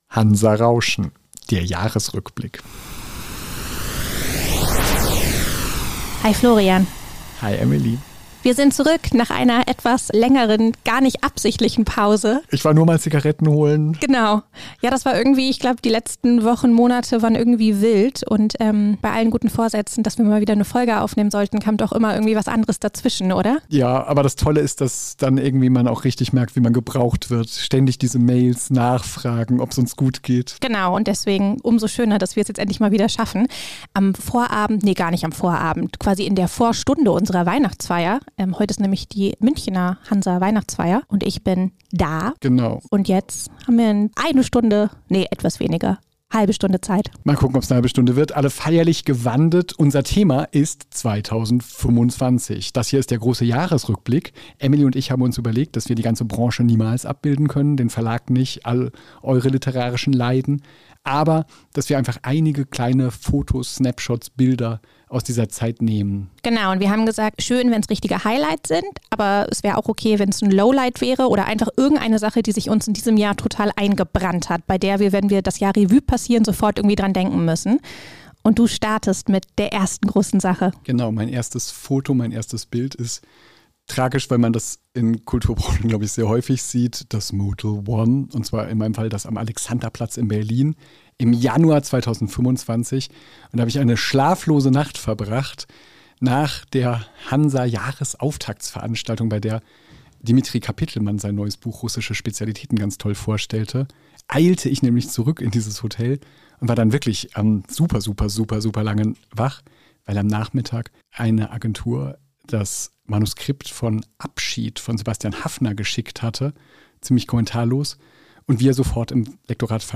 Aber zum Jahresende, kurz vor Beginn der Weihnachtsfeier, haben sie doch noch eine Aufnahme geschafft.